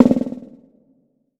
TC PERC 13.wav